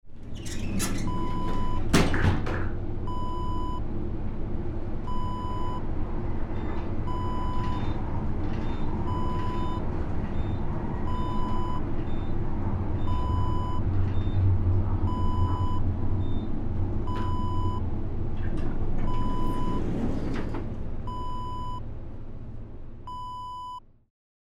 Elevator Interior Wav Sound Effect #4
Description: The sound of elevator doors closing, elevator moving, beeping as it passes floors, and doors opening
Properties: 48.000 kHz 24-bit Stereo
A beep sound is embedded in the audio preview file but it is not present in the high resolution downloadable wav file.
Keywords: elevator, hotel, office, ding, bell, lift, moving, going up, down, floor, interior, background, ambience
elevator-interior-preview-4.mp3